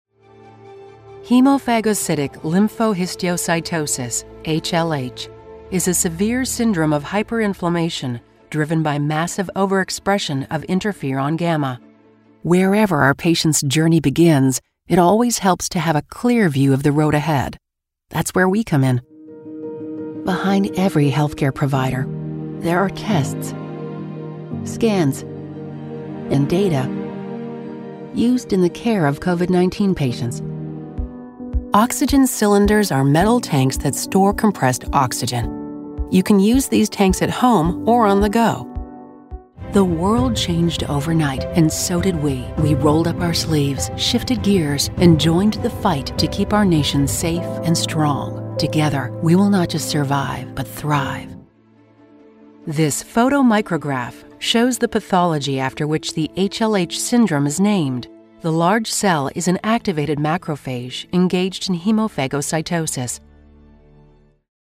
Narration médicale
Et la capacité de lire un jargon médical difficile !
Avec une cabine StudioBricks et un micro Sennheiser, je produis efficacement un son de qualité studio.
Sennheiser 416 ; cabine StudioBricks ; Adobe Audition ; mixeur Yamaha AG03 ; Source-Connect.